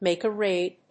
アクセントmàke a ráid